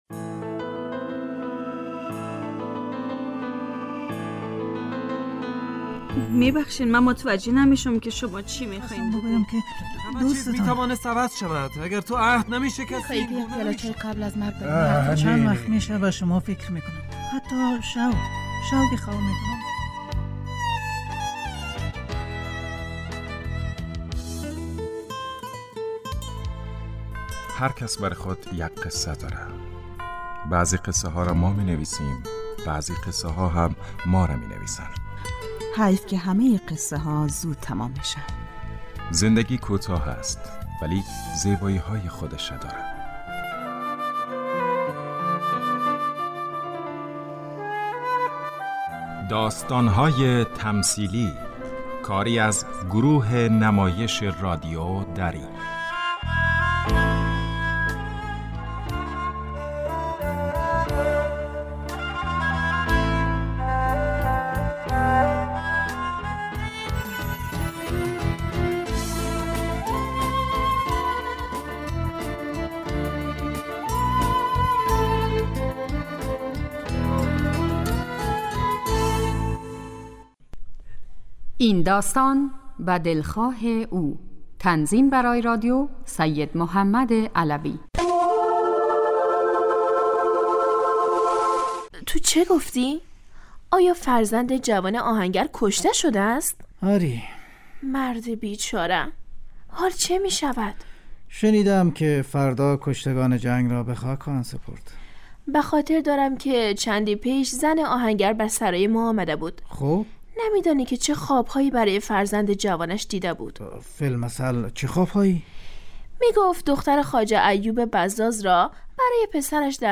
داستان تمثیلی / به دلخواه او